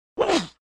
target_impact_grunt1.ogg